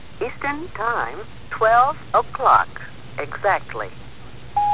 Registrazioni sonore di happening Fluxus